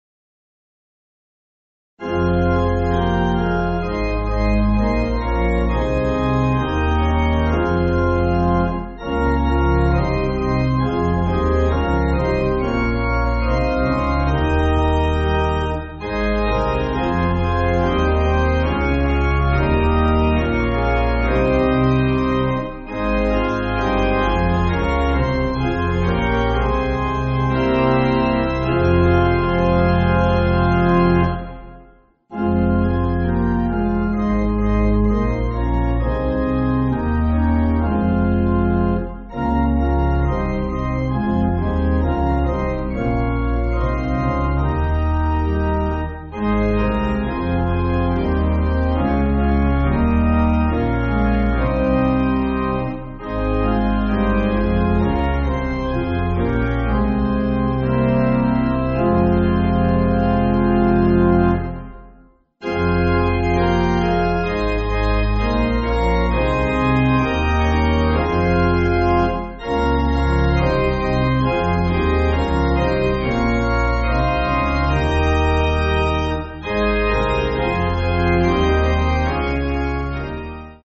Organ
(CM)   3/Fm